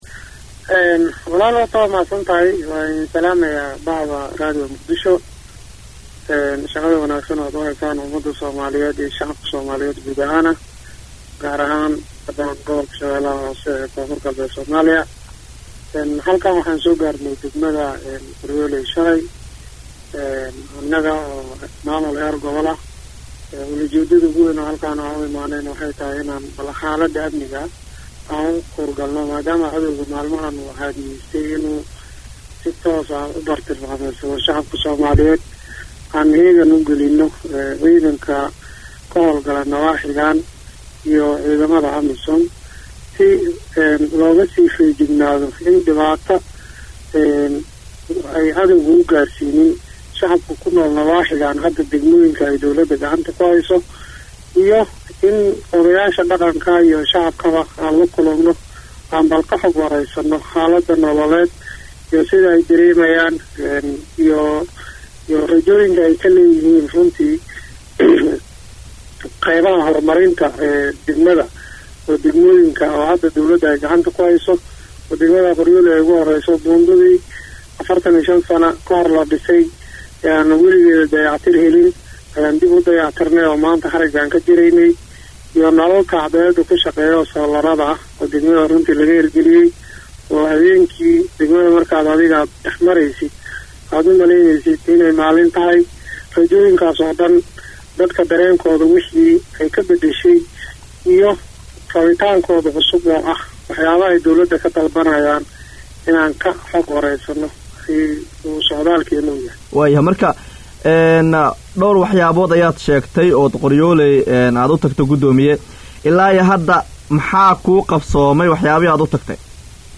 Guddoomiyaha Gobolka Shabeelaha hoose Ibraahim Aadan Najax oo la hadlay Radio Muqdisho Codka Jahmuuriyadda Soomaaliya ayaa sheegay in Socdaal ay ugu kuur galayaan Xaalada Aminiga iyo mida nolaleed ay ku tageen deegaano ka tirsan Shabeelaha hoose.
waraysi-gudoomiyaha-sh-hoose-.mp3